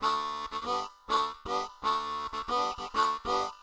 Harmonica Rhythm 04 (Loopable)
描述：Harmonica rhythm played on a marine band harmonica key of G.
标签： Harmonica Rhythm Key G
声道立体声